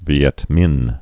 (vē-ĕtmĭn, vēĭt-, vyĕt-)